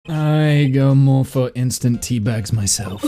Play, download and share Always Gagging original sound button!!!!